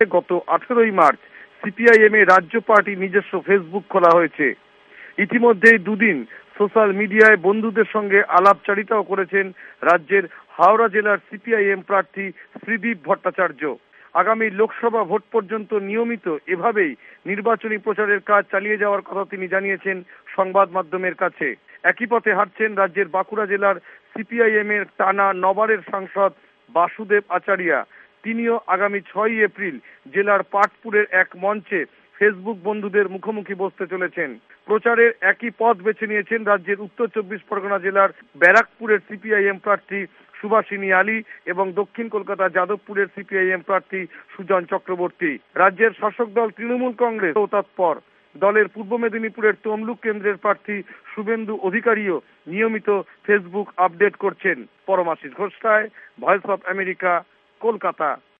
ভয়েস অব আমেরিকার কোলকাতা সংবাদদাতাদের রিপোর্ট